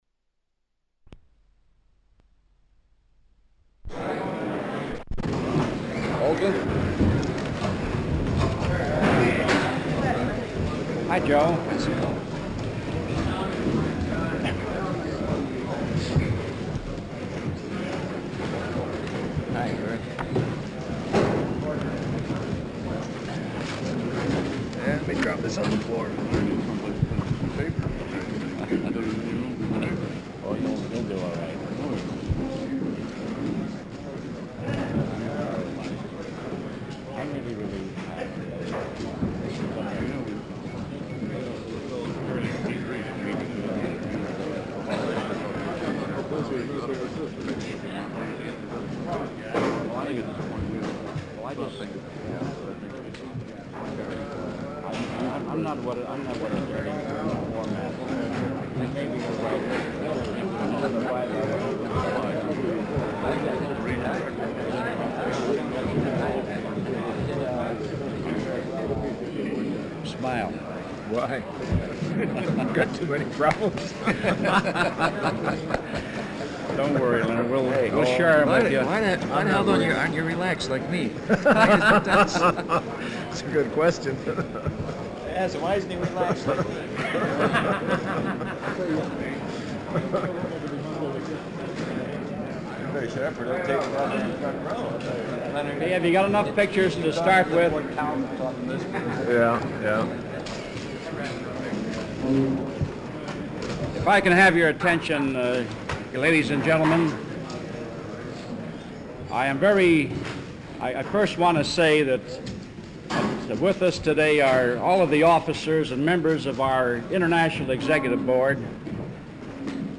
Walter P. Reuther Digital Archive · UAW President Leonard Woodcock - Press Conference - Solidarity House, Detroit, MI · Omeka S Multi-Repository